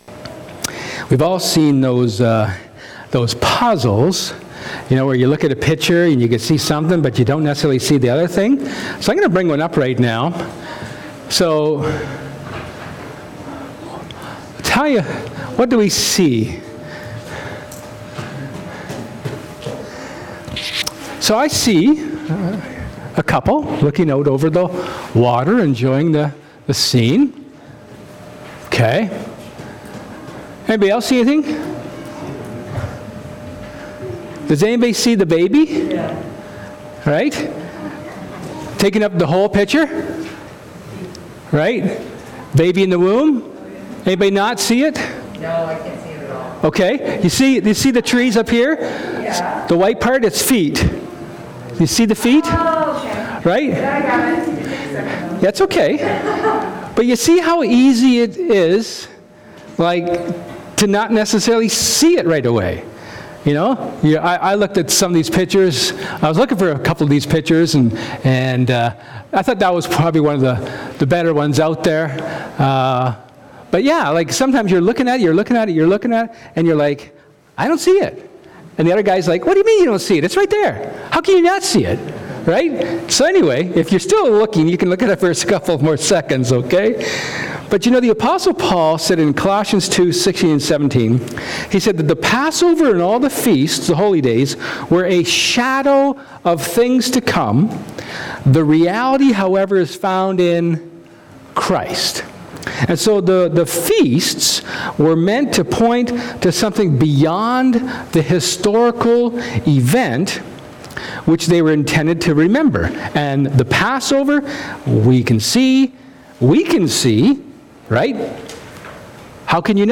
Leviticus 23:33-44 Service Type: Sermon